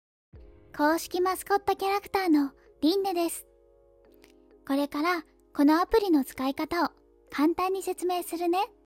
丁寧・高品質・リーズナブルなプロの女性ナレーターによるナレーション収録
可愛らしいキャラクター